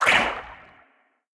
Sfx_creature_nootfish_flinch_01.ogg